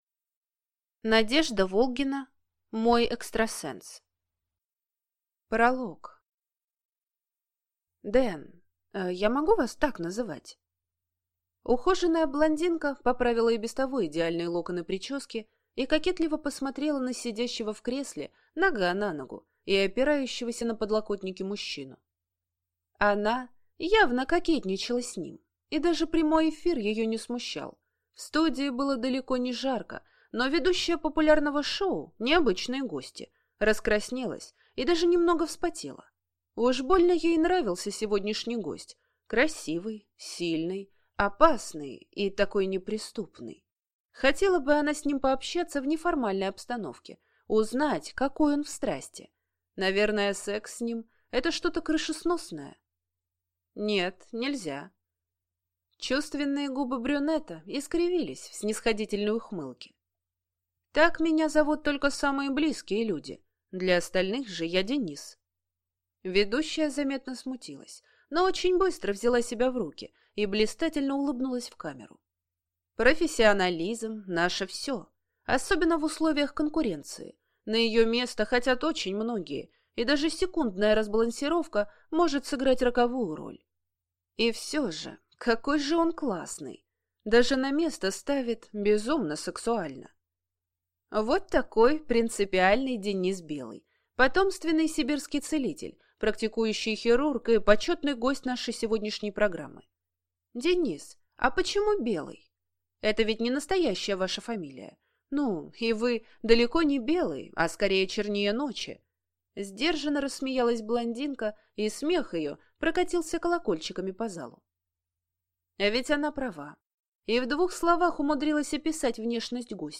Аудиокнига Мой экстрасенс | Библиотека аудиокниг